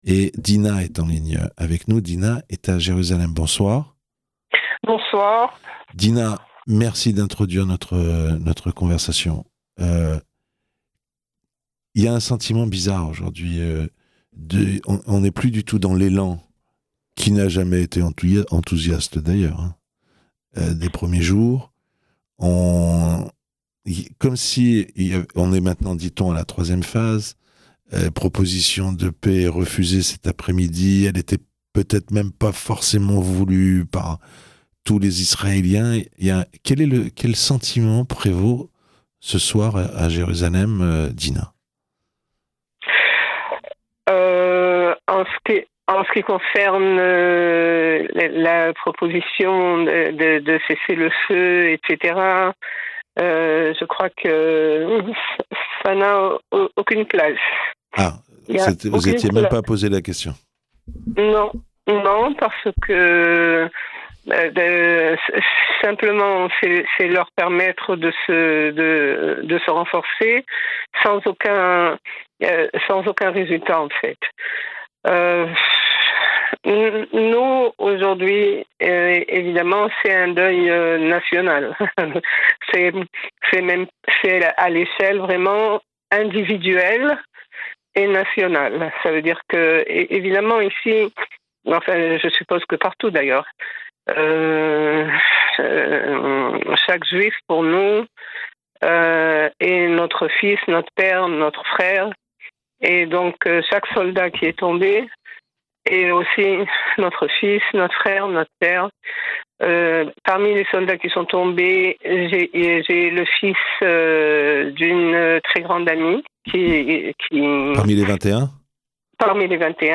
Témoignage de femmes israéliennes qui ont leurs fils engagés dans l'armée à Gaza et à la frontière libanaise : dormir avec le téléphone portable dans son lit, aller au travail en faisant semblant que tout va bien, attendre constamment des nouvelles, se forcer à ne pas regarder les réseaux sociaux.